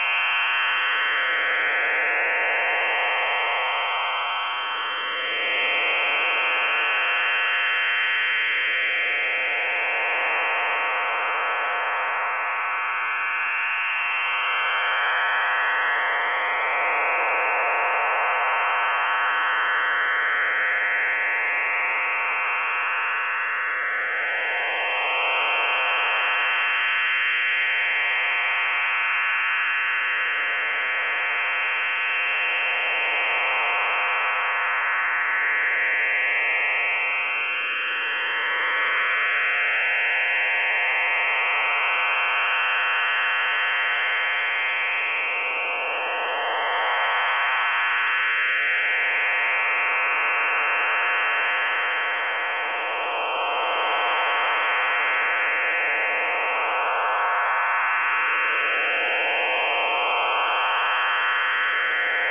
File:CIS-12 BPSK 2015-10-12T06-41-04Z 9192.2kHz.mp3 - Signal Identification Wiki